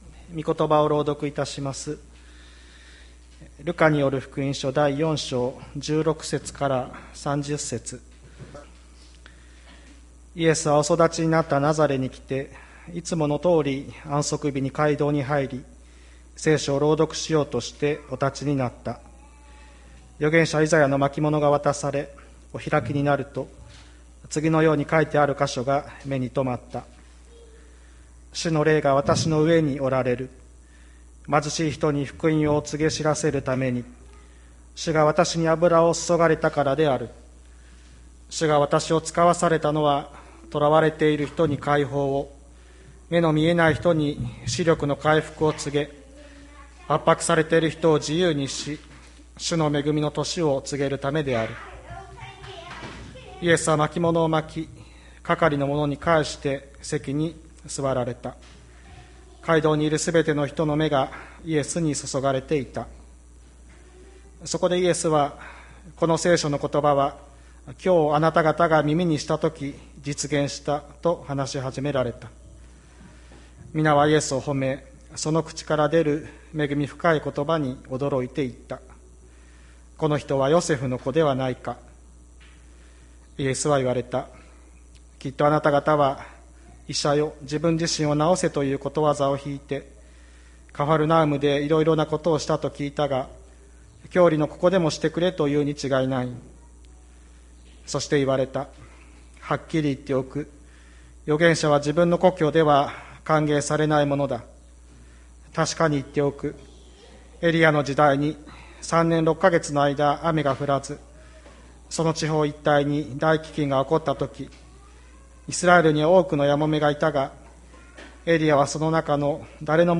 2021年07月11日朝の礼拝「聖書がわかる」吹田市千里山のキリスト教会